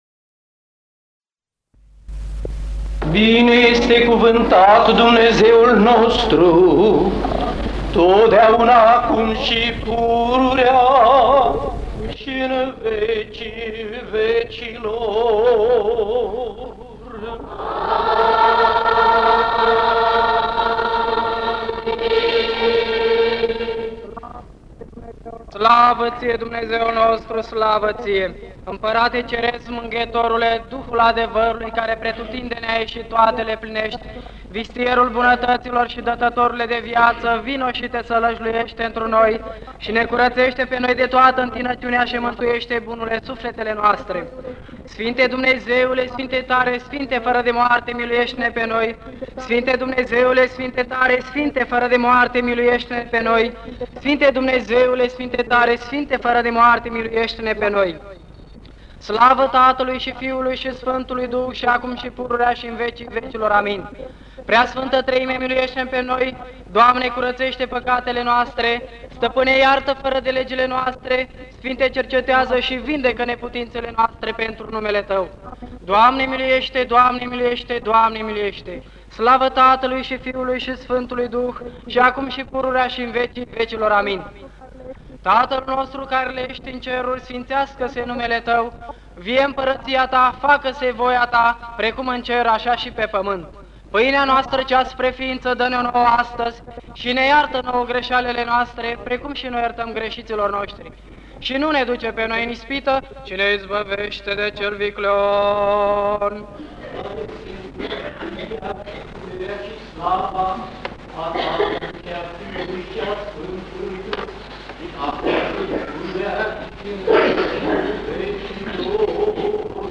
P AVECERNI ŢA MARE - Biserica "Sf. Nicolae - S�rbi" Bucureşti 1983 Descarcă �n format "mp3" Texte alternatif